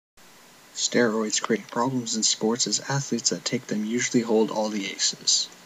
ネイティブ発音の音声はこちら。